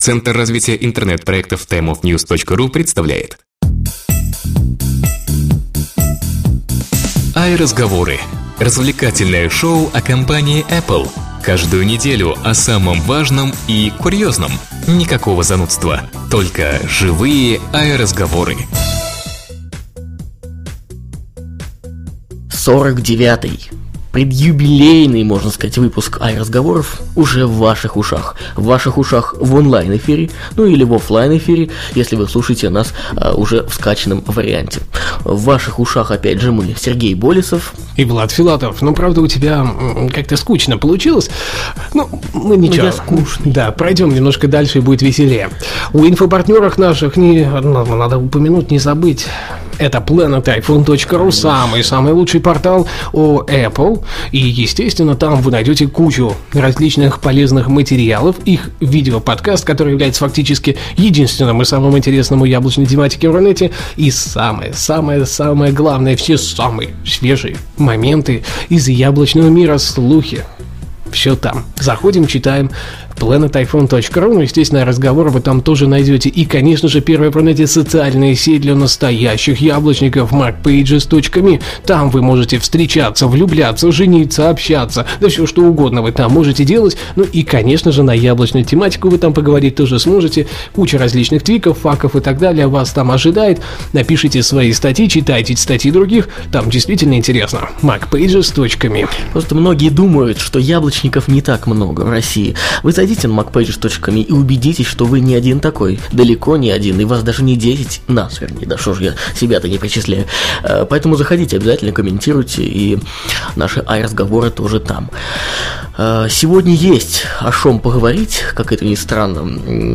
АйРазговоры #49 "АйРазговоры" - еженедельный развлекательный подкаст о Apple
stereo Ведущие аудиошоу в свободной и непринужденной манере расскажут вам обо всех самых заметных событиях вокруг компании Apple за прошедшую неделю. Никакой начитки новостей, занудства, только живые "АйРазговоры".